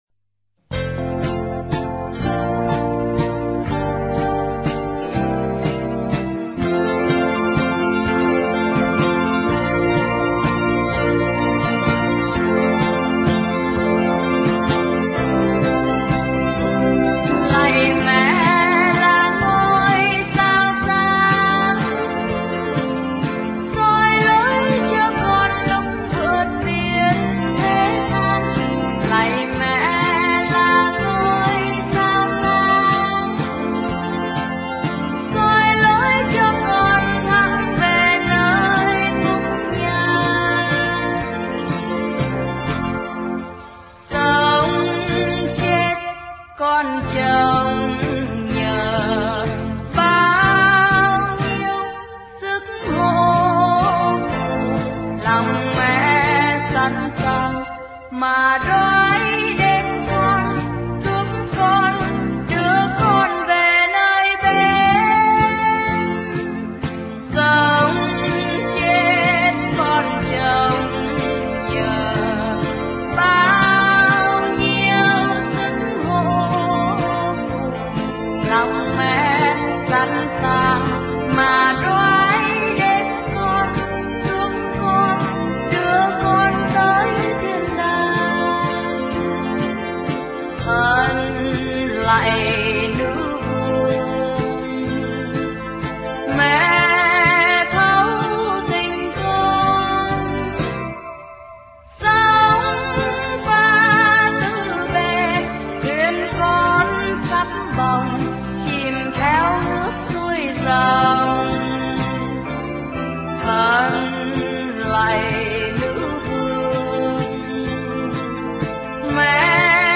Dòng nhạc : Đức Mẹ